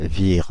The Vire (French pronunciation: [viʁ]
Fr-Paris--Vire.ogg.mp3